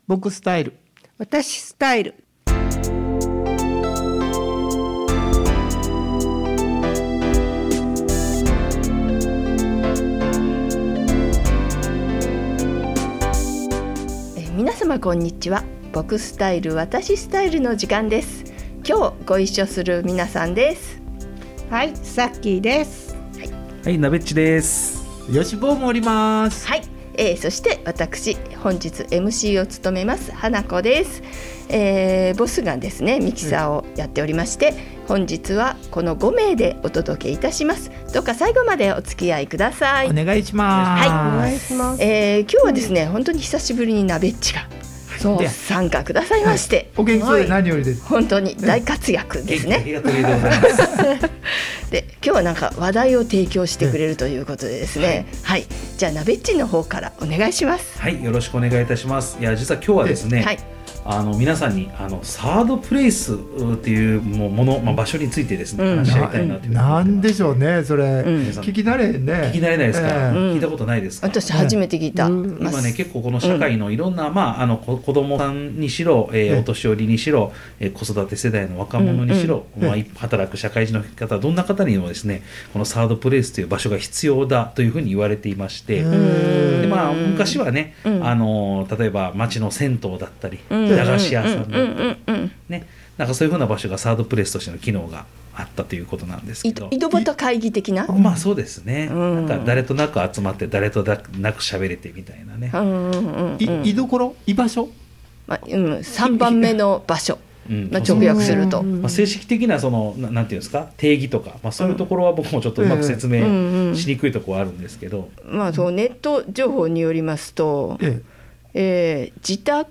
番組をお聞きください 収録中 5/20 ハートフルフェアーにて「ぼくスタ」にてサードプレイスについてトークいたしました。
場所：相生市総合福祉会館